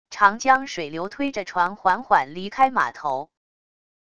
长江水流推着船缓缓离开码头wav音频